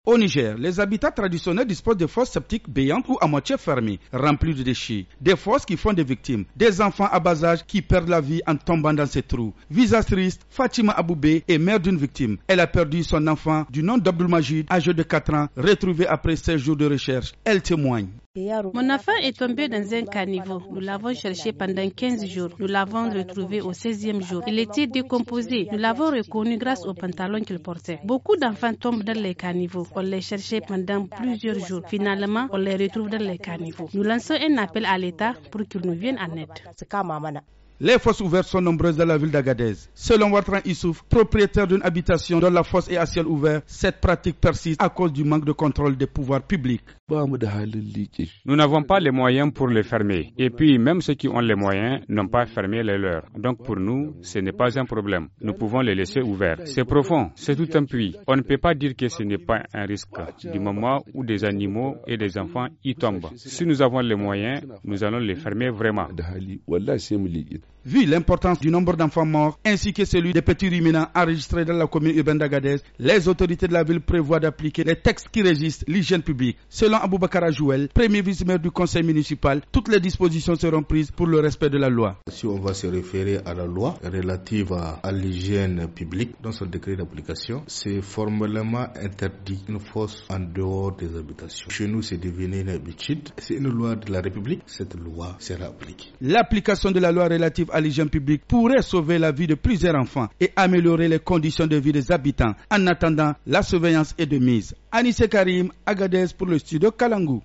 Ces morts sont des enfants âgés de 3 à 5 ans en décomposition après plus de 2 semaines de recherche. Reportage